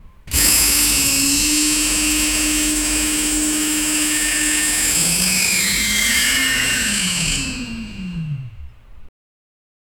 heavy-air-pump-rhythmical-5bma3bps.wav